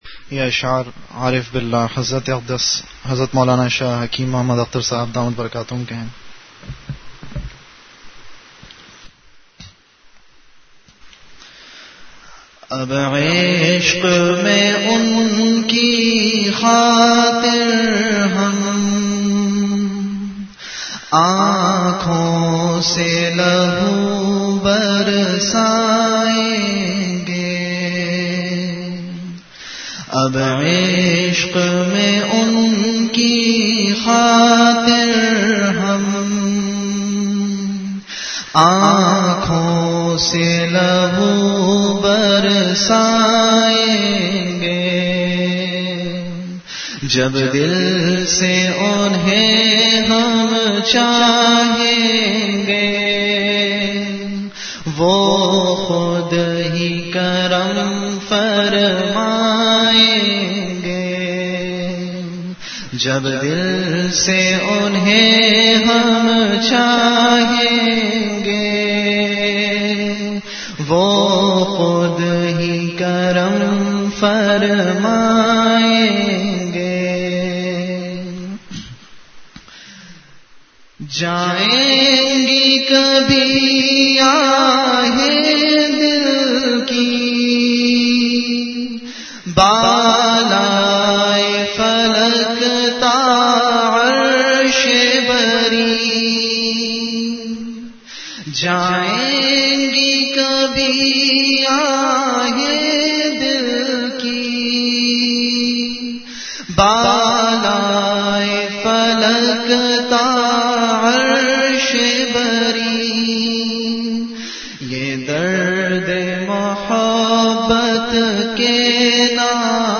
Delivered at Home.
Ashaar
Event / Time After Isha Prayer